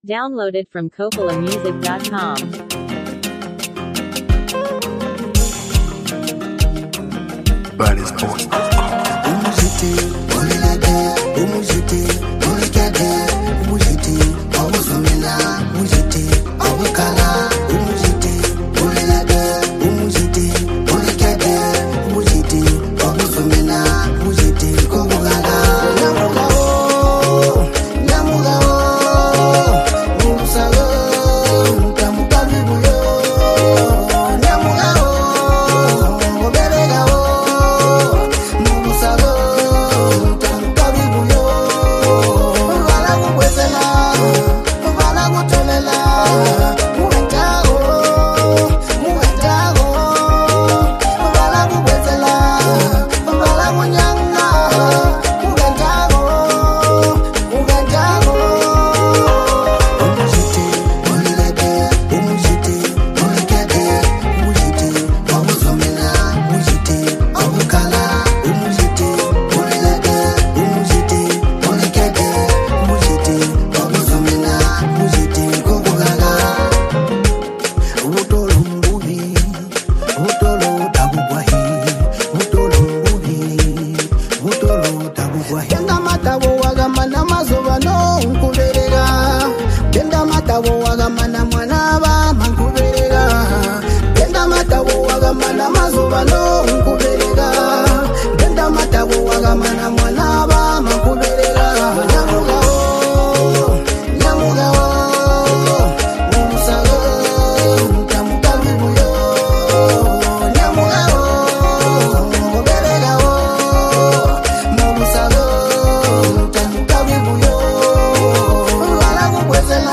vibrant and relatable song
signature storytelling and powerful lyrical style